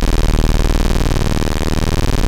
OSCAR 1  D#1.wav